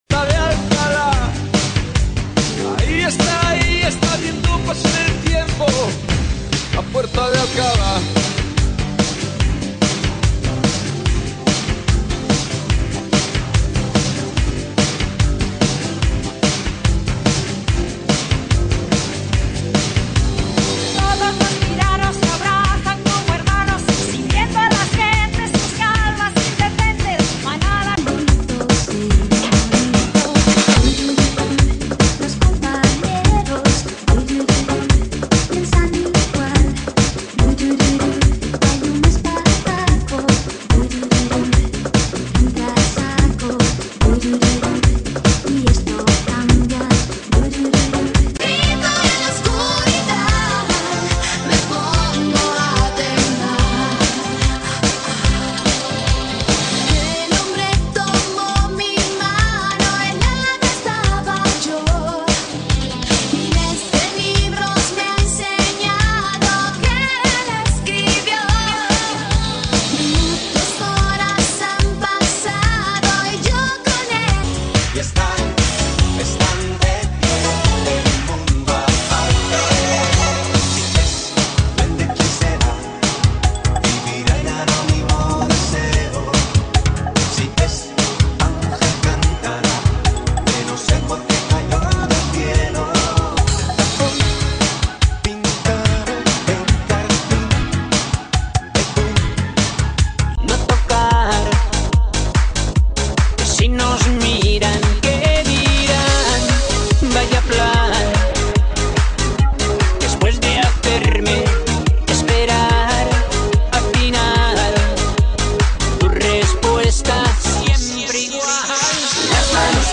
GENERO: MUSICA DISCO